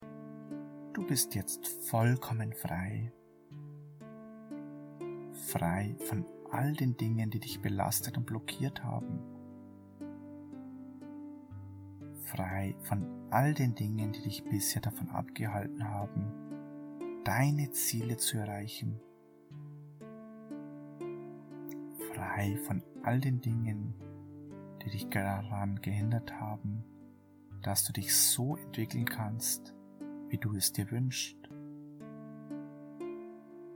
Unsere entspannende Suggestionen können Ihnen dabei helfen, die Häufigkeit und Schwere der Anfälle zu reduzieren.
Enthaltene MP3: S6006 – Migräne effektiv loswerden – Hauptsitzung